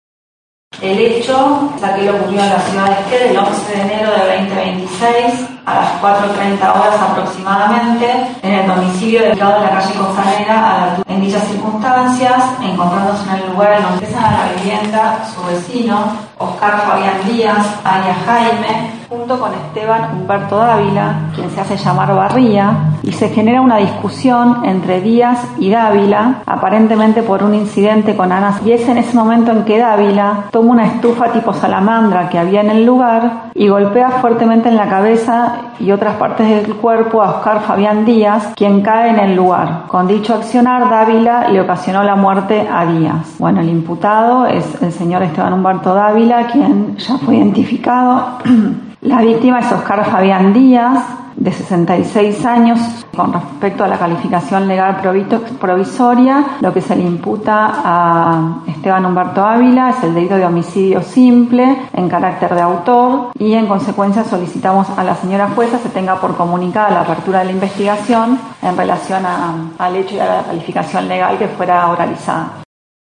Noticias de Esquel fue el único medio presente en la audiencia, te ofrece el audio del momento de la acusación y luego la declaración del detenido.